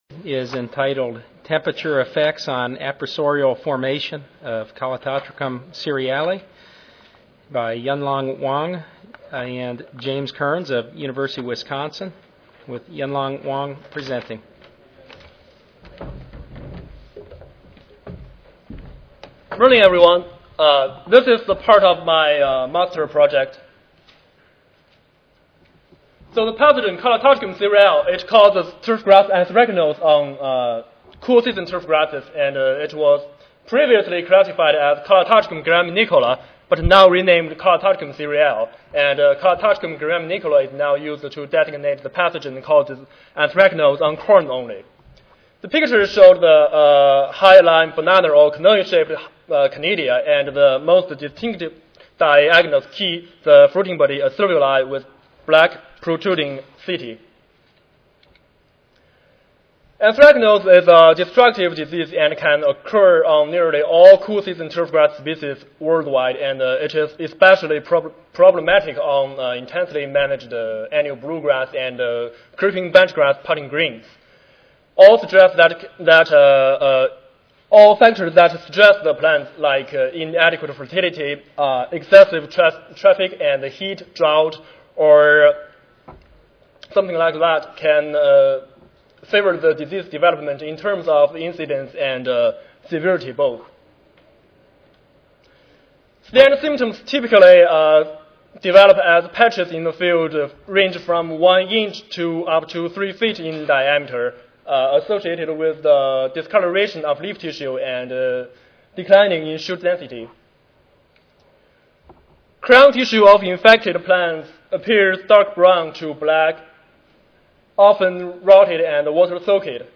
University of Wisconsin Madison Recorded Presentation Audio File